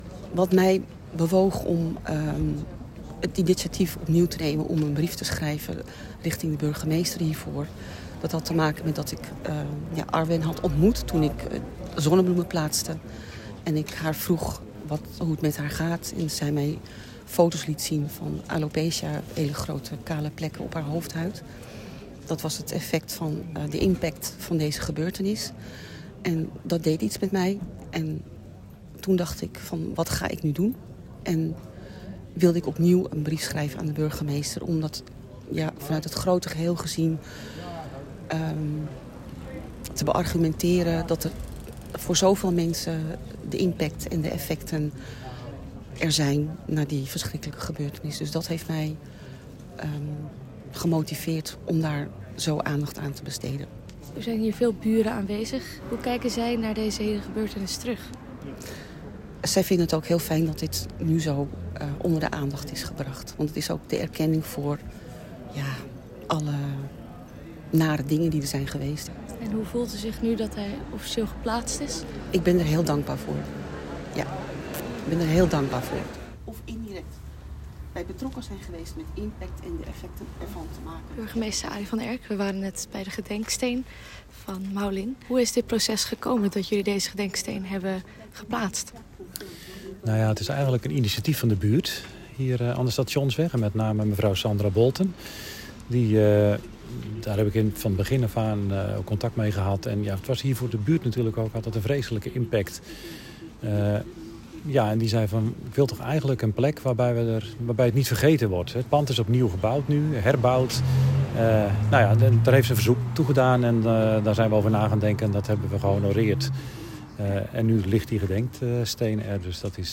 audioreportage